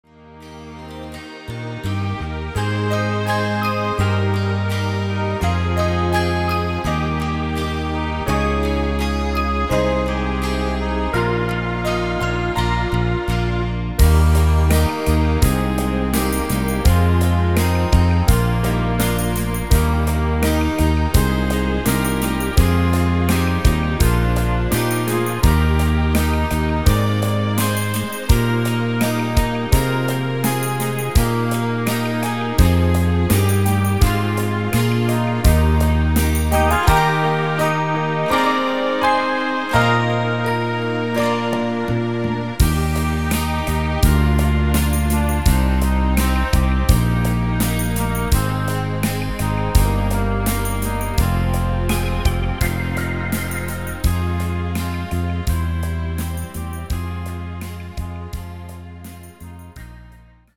Rhythmus  Slow 8 Beat
Art  Englisch, Weibliche Interpreten, Weihnachtslieder